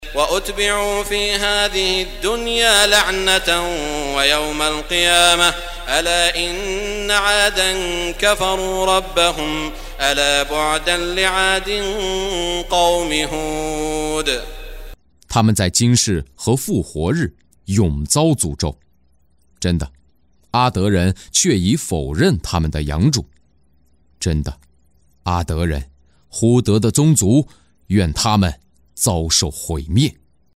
中文语音诵读的《古兰经》第（呼德章）章经文译解（按节分段），并附有诵经家沙特·舒拉伊姆的诵读